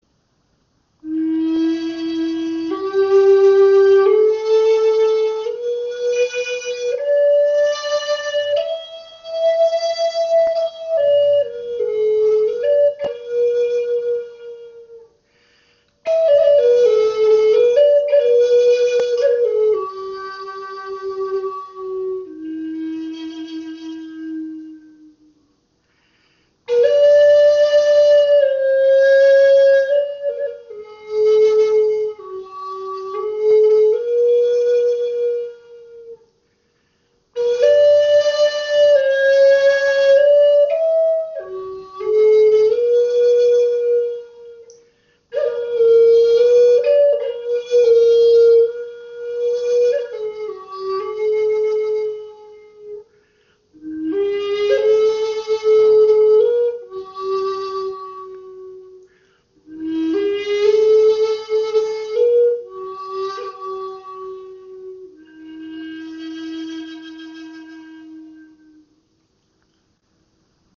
Klangbeispiel
Diese Gebetsflöte in E ist auf 432 Hz gestimmt. Sie wurde aus dem Holz einer Eberesche geschaffen und abschliessend geölt, so dass sie ein seidenglänzendes Finish hat.
Diese Flöte hat einen angenehmen Luftwiderstand und lässt sich einfach modulieren.